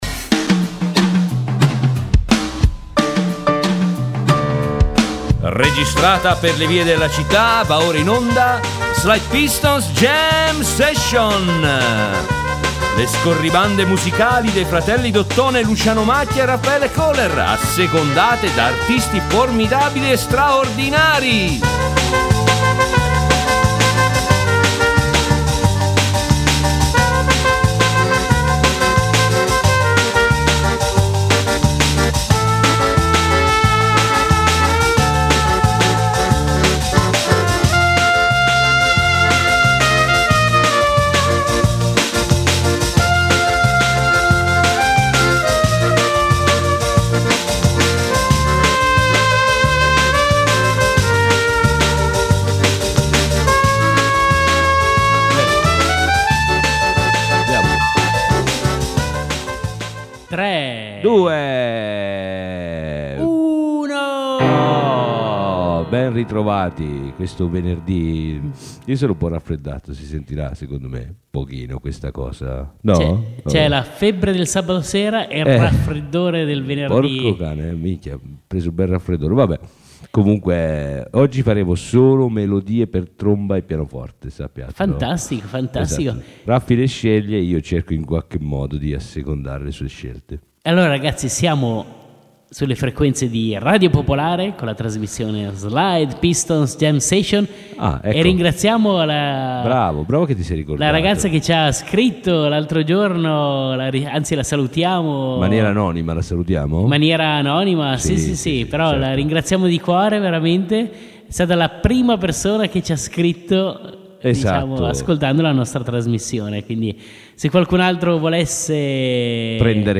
In onda le scorribande musicali dei due suonatori d’ottone in giro per la città, assecondate da artisti formidabili e straordinari.